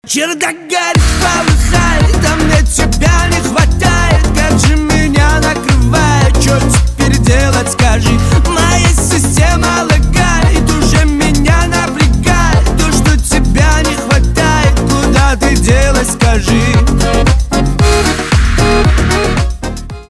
кавказские
битовые , аккордеон , грустные , басы